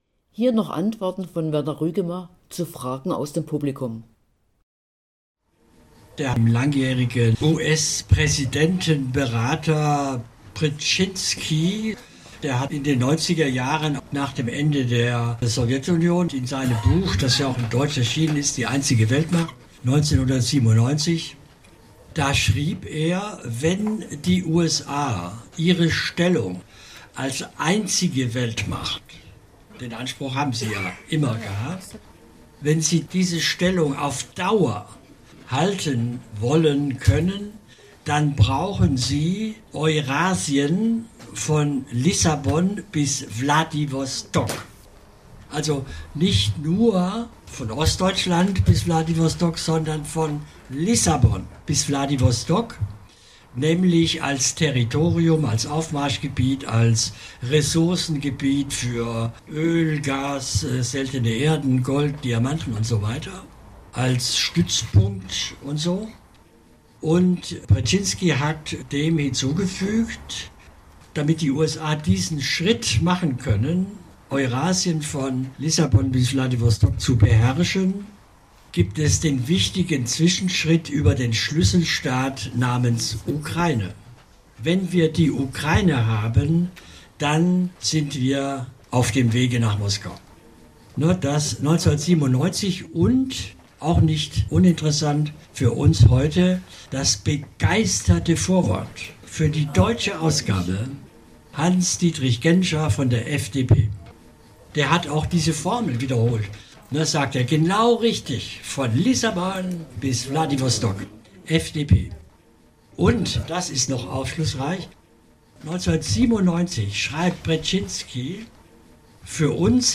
beim Friedensratschlag am 30.11.2024 in Kassel